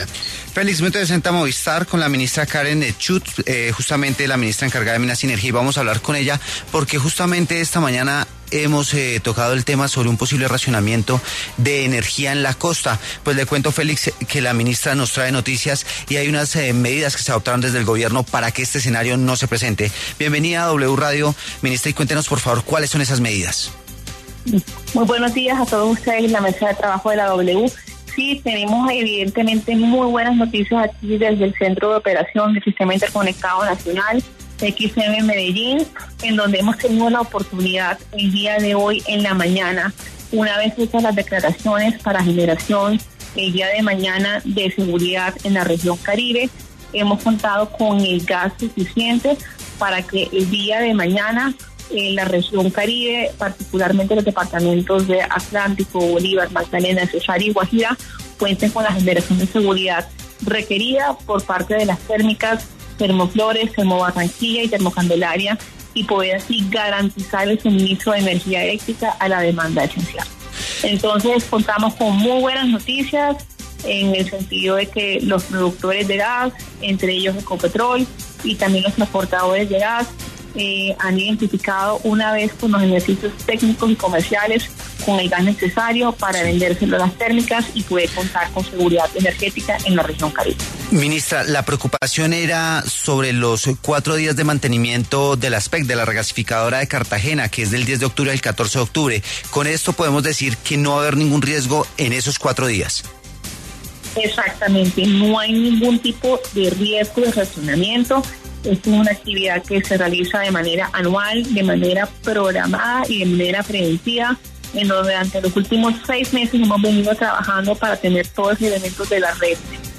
En entrevista con W Radio, la ministra encargada del Ministerio de Minas y Energía, Karen Schutt, dio un parte de tranquilidad al país y aseguró que no habrá racionamiento de energía o gas en ninguno de sus sectores, respondiendo así a la incertidumbre que tenían los grandes industriales de la Costa, los cuales no fueron priorizados en recientes medidas que definieron la priorización de entrega de gas natural mientras se realiza el mantenimiento de la regasificadora de Cartagena entre el 10 y 14 de octubre.